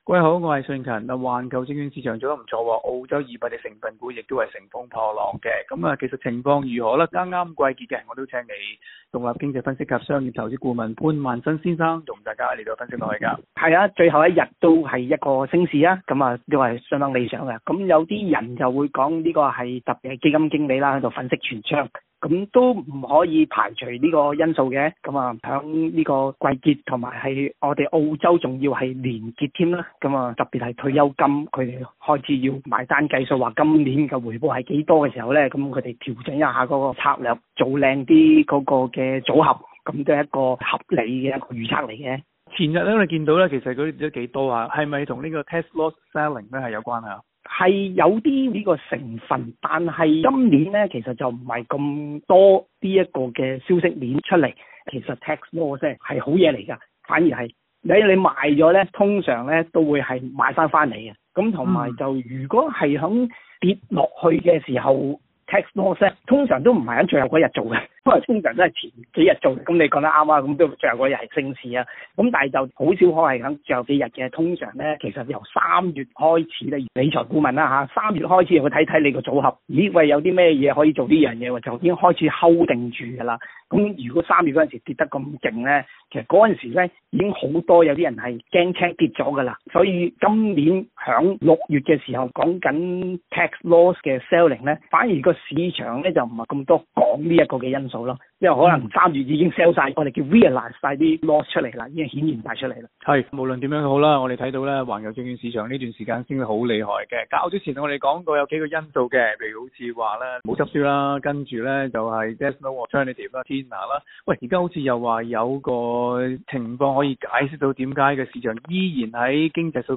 （详情请大家收听今期的访问。）